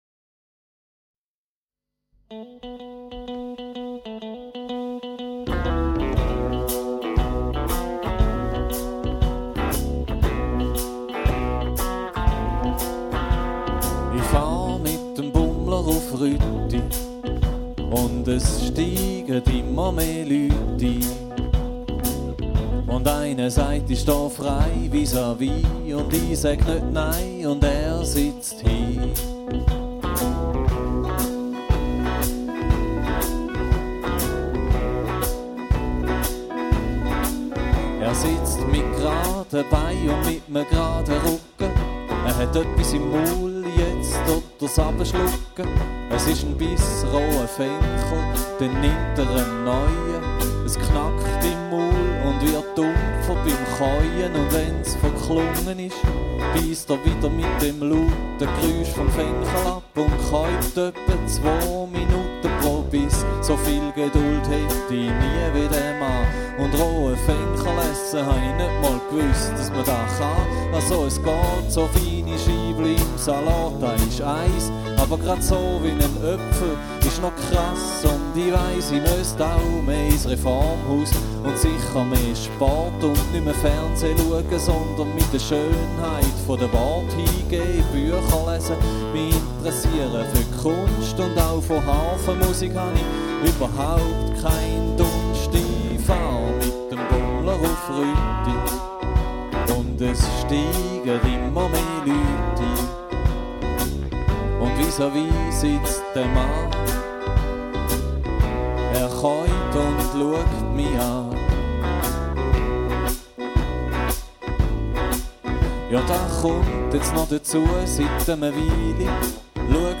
Wortwitz und Biomusik